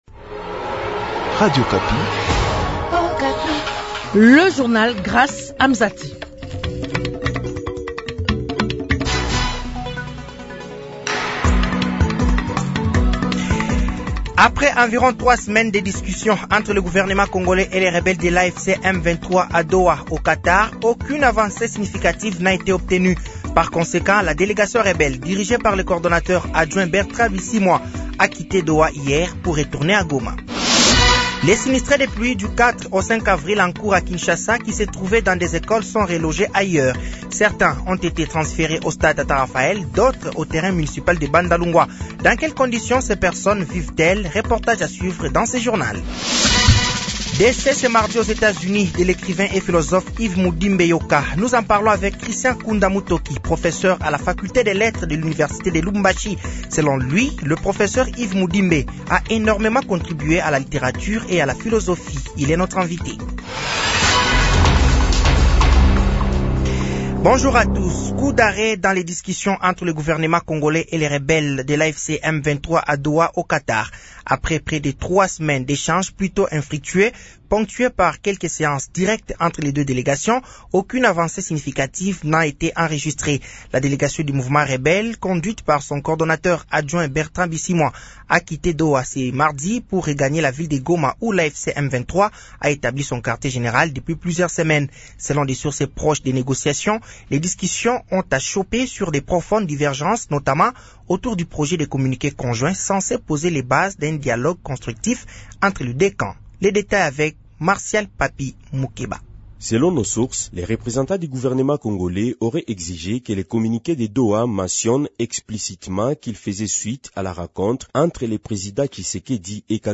Journal français de 15h de ce mercredi 23 avril 2025